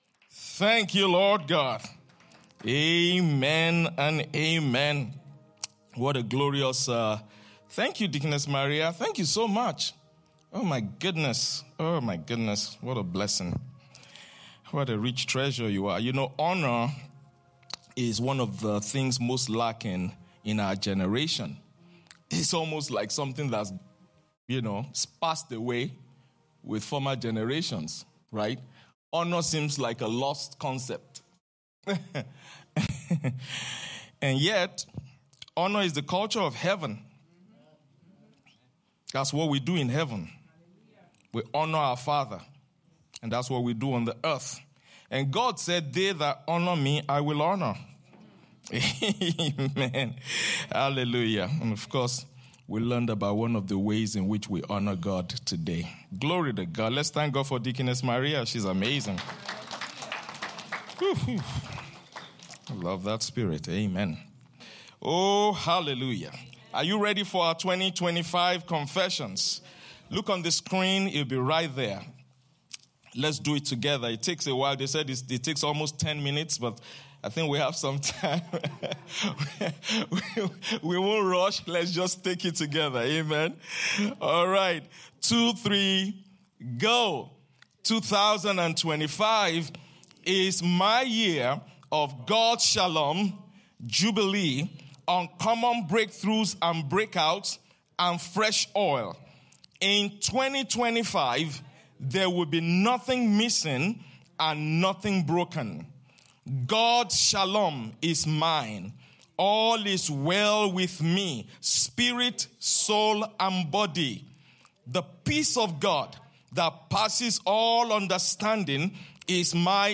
Sermons – Abundant Life International Church podcast